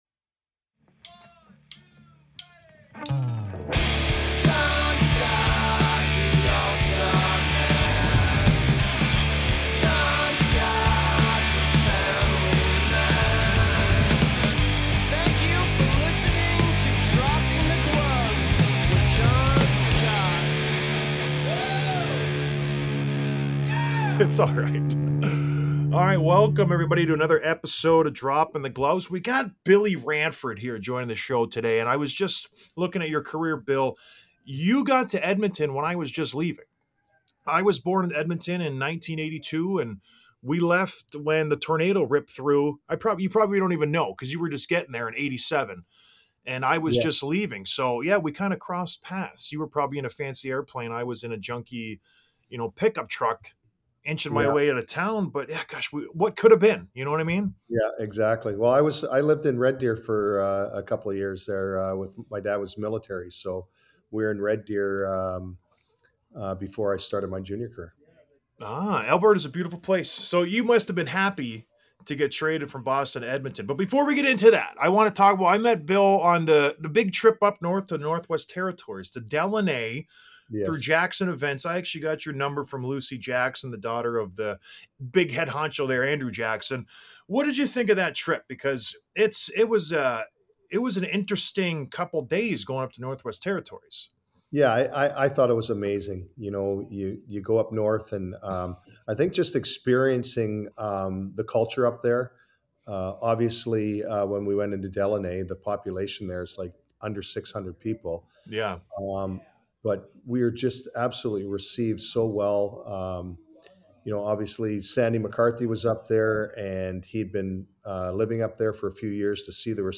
Interview w/ Bill Ranford